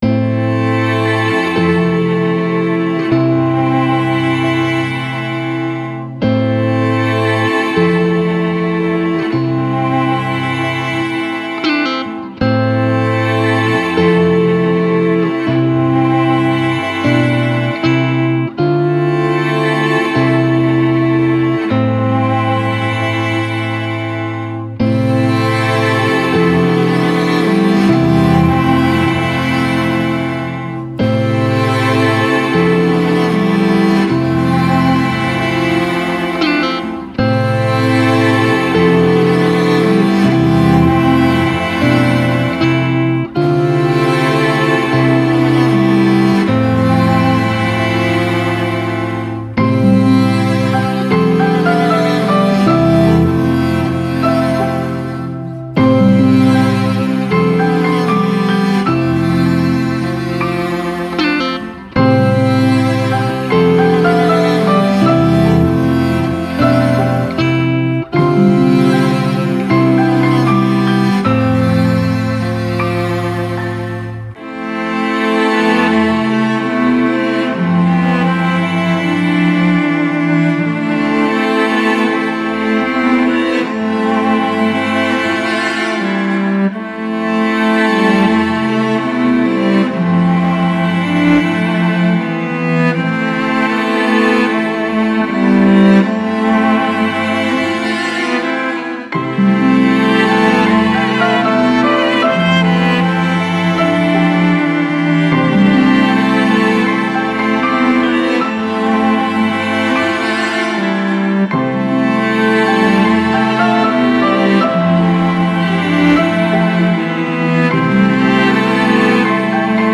Ambient, Soundtrack, Classical, Hopeful